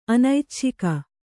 ♪ anaicchika